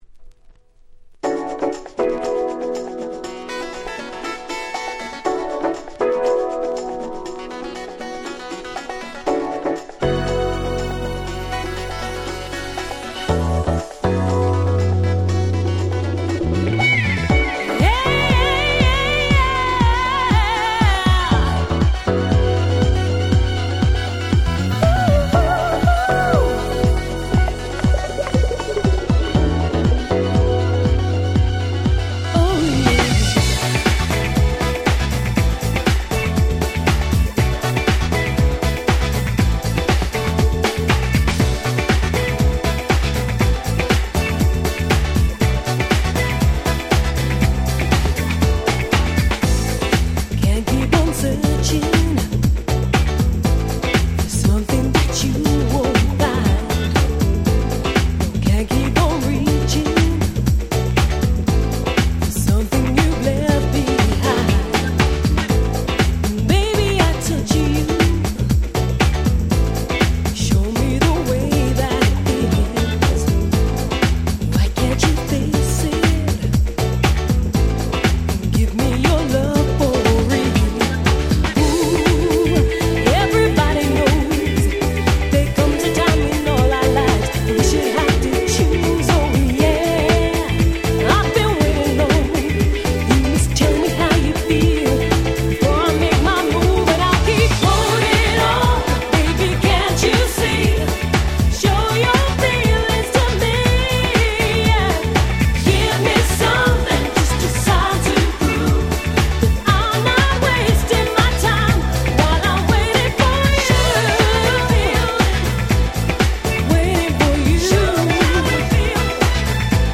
93' UK R&B/Acid Jazz Classic !!
疾走感がたまらない、本当に格好良い曲です。
シンクトゥワイス 90's R&B アシッドジャズ ヴォーカルハウス キャッチー系